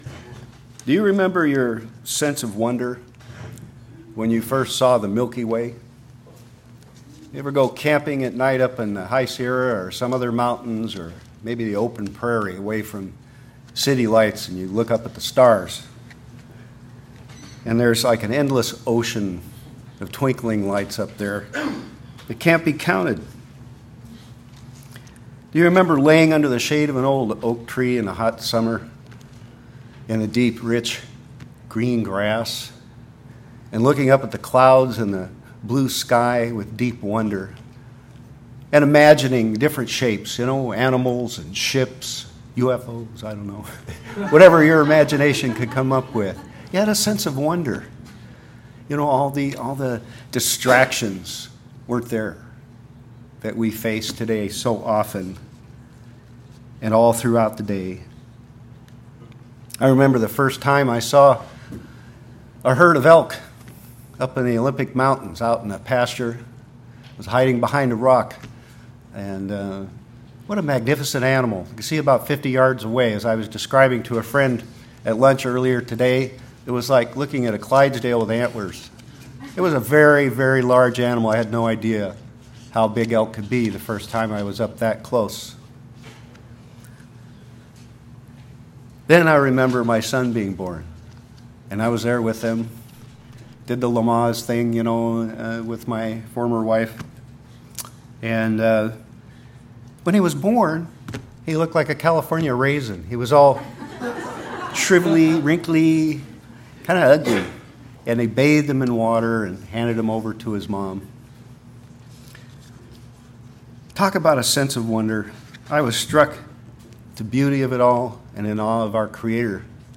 Listen to this sermon and learn how you can renew your wonder, and meditate on God’s wonders small and great. Learn how to recapture your joy even when things seem to be unbearable and remember the wondrous things God has done for you, and especially the indescribably wonderful future He has in store for you!